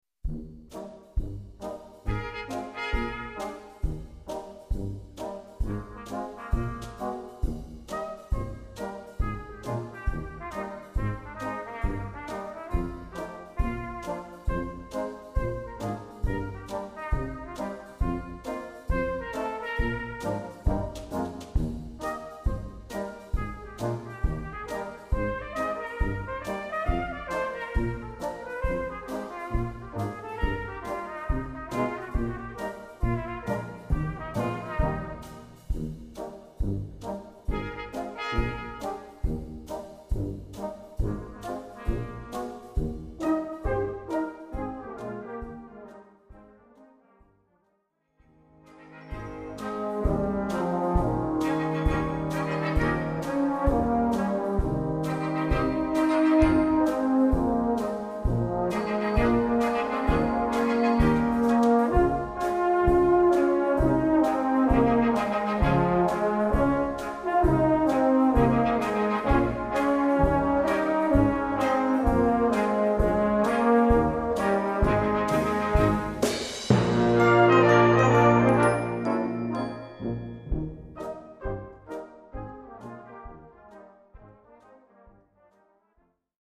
Répertoire pour Brass band